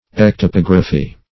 Search Result for " ectypography" : The Collaborative International Dictionary of English v.0.48: Ectypography \Ec`ty*pog"ra*phy\, n. [Ectype + -graphy.] A method of etching in which the design upon the plate is produced in relief.